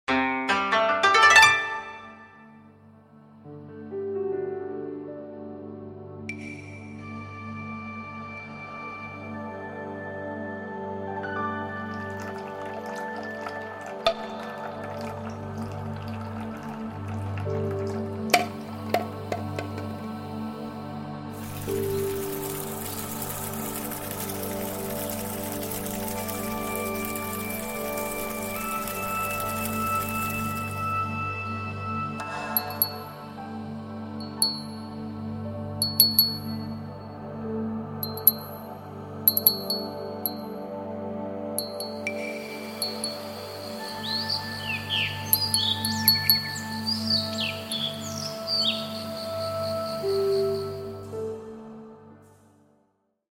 What pleaceful sounds would you expect to hear from a traditional Japanese garden?